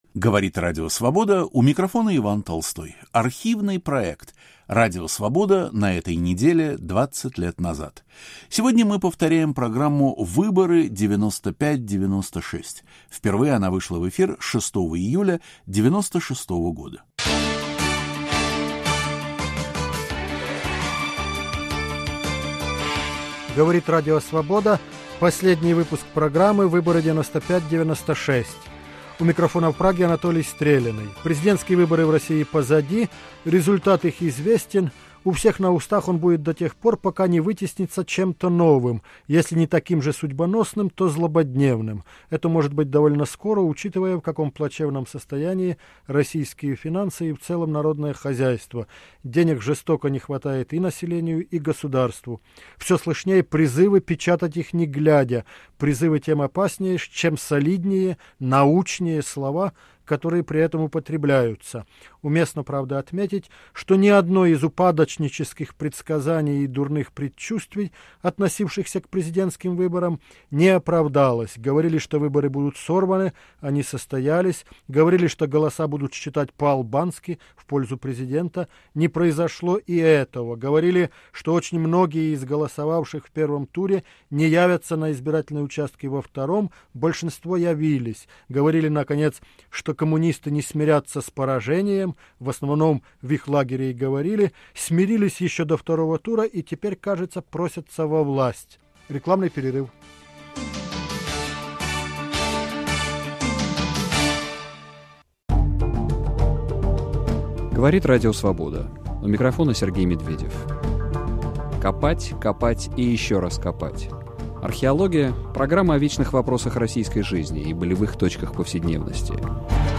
Итоговая программа.